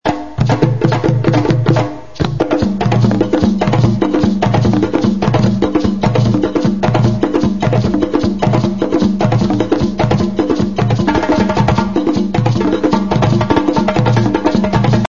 percussions